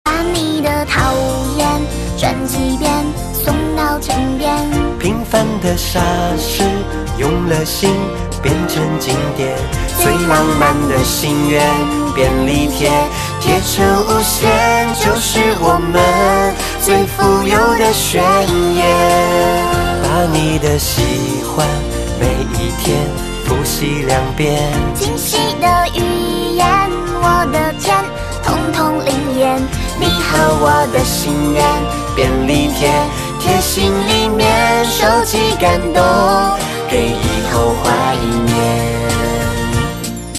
M4R铃声, MP3铃声, 华语歌曲 58 首发日期：2018-05-13 08:51 星期日